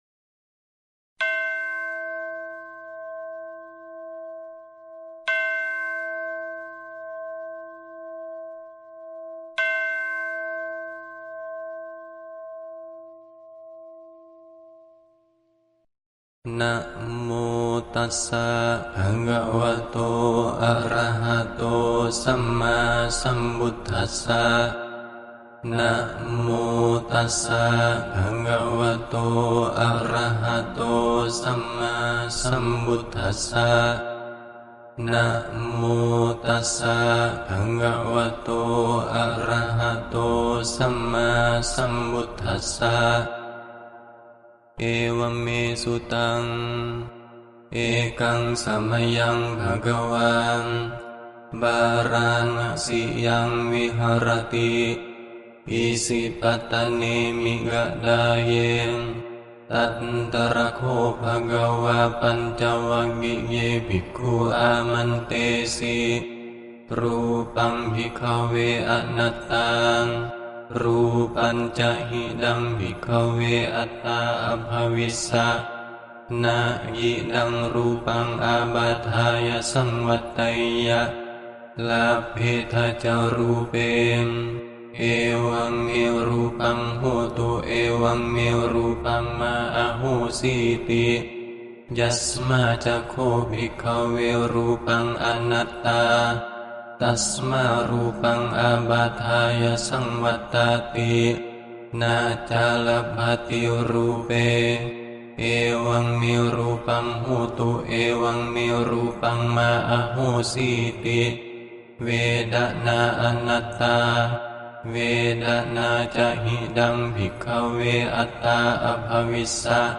Home > 音声と動画 > 音声ファイル 読経 追加日: 2025年6月17日 記録年(月): Anttalakkhaṇasuttaṃ 無我相経 【解説】 パーリ相応部経典の『無我相経』は、ゴータマ、ブッダの最初の説法とされる『転法輪経』が説かれた五日後に五人の比丘に説かれた経で、仏教の中心的テーマである無我について問答様式で示されています。